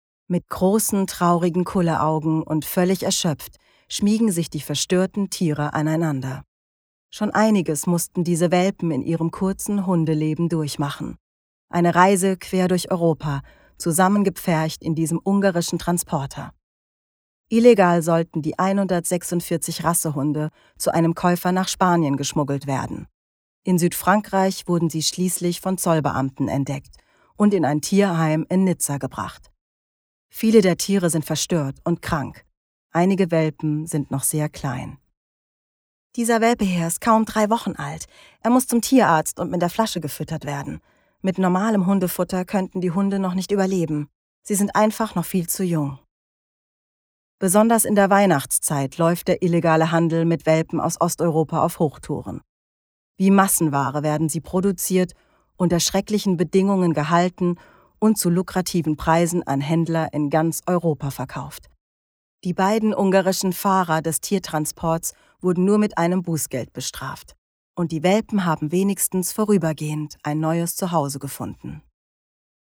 Posted in: Voice-Over / Dokumentation Posted on 3.
tv-beitrag-welpen-demo.mp3